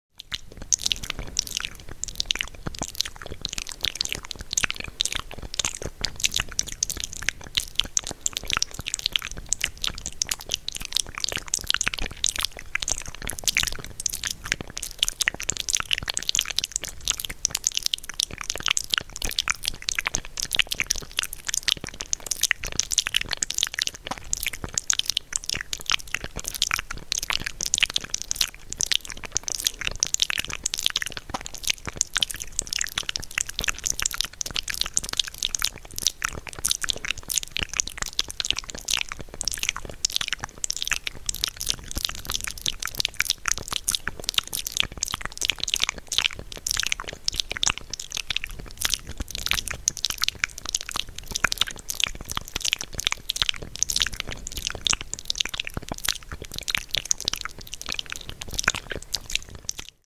MM2 mouth sounds